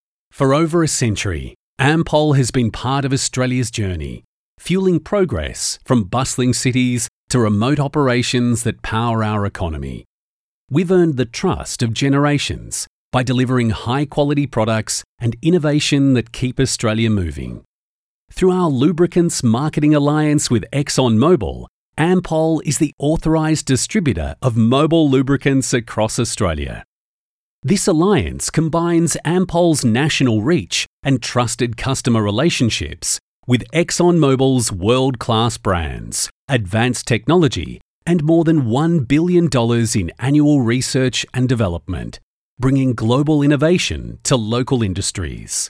Male
English (Australian)
Warm and very flexible. Hard sell to natural with a great ability to bring the script to life.
Corporate
Words that describe my voice are Warm, Natural, Friendly.
1013Corporate_Vid.mp3